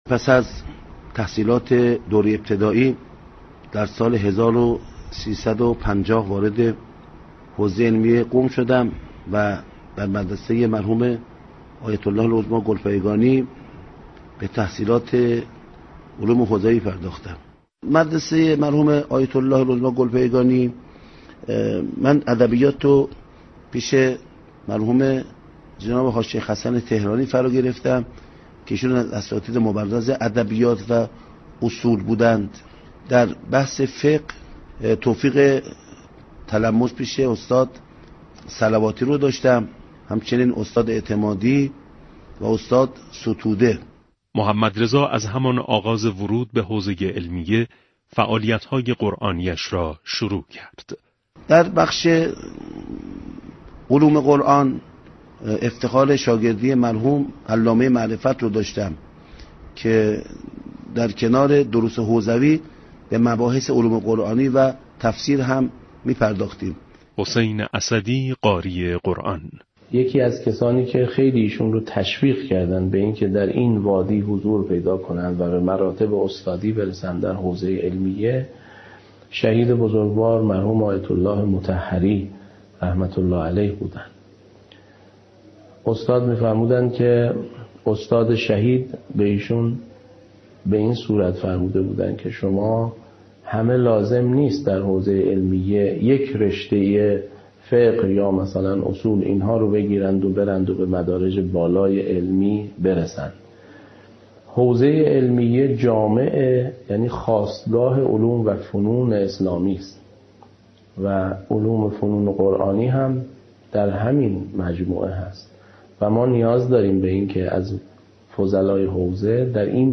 مستند نگارستان رادیو معارف از 22 تا 24 شهریورماه پخش می‌شود.